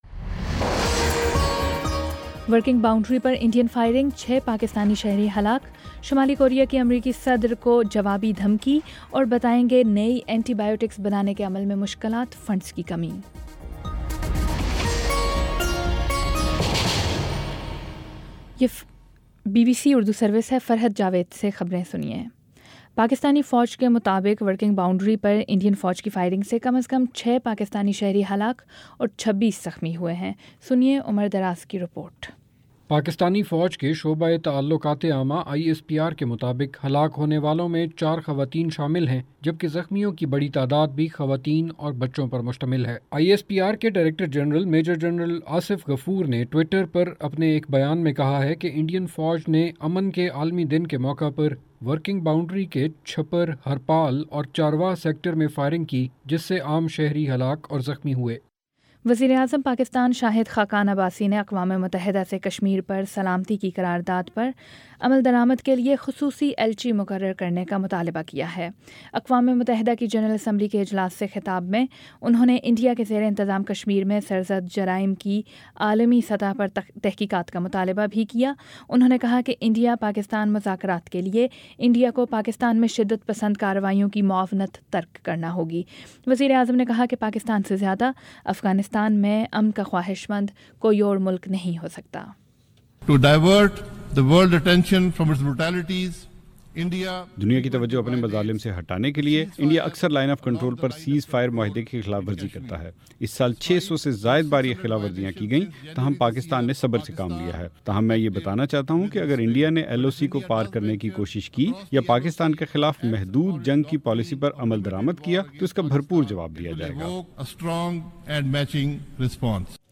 ستمبر 22 : شام چھ بجے کا نیوز بُلیٹن